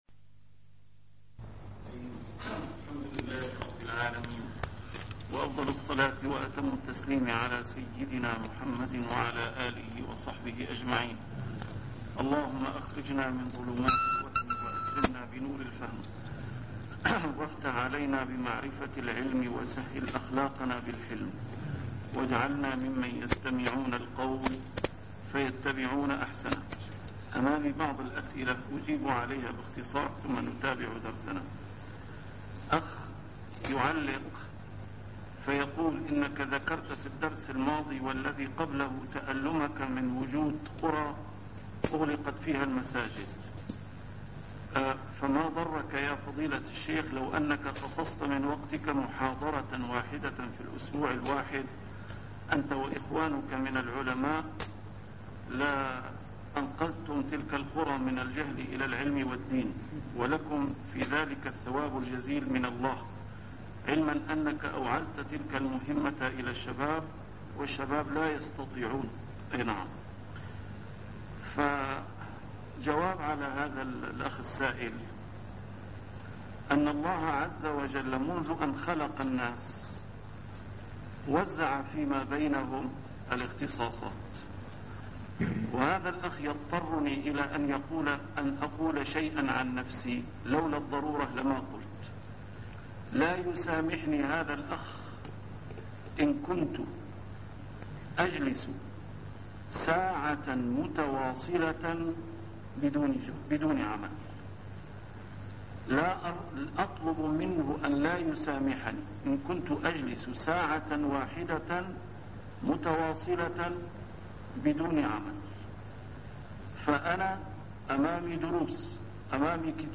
A MARTYR SCHOLAR: IMAM MUHAMMAD SAEED RAMADAN AL-BOUTI - الدروس العلمية - شرح الأحاديث الأربعين النووية - تتمة شرح الحديث الثاني والأربعين وهو الحديث الأخير: حديث أنس (يا ابن آدم إنك ما دعوتني ورجوتني غفرت لك) 142